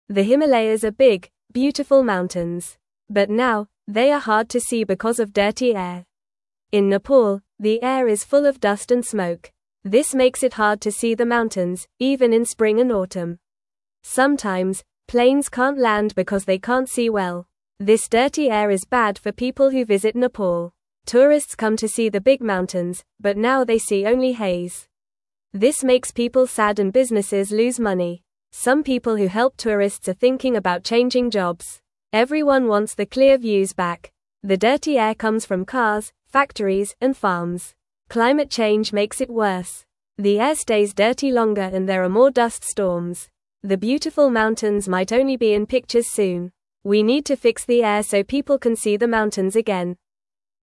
Fast
English-Newsroom-Beginner-FAST-Reading-Dirty-Air-Hides-Beautiful-Himalayas-from-Everyone.mp3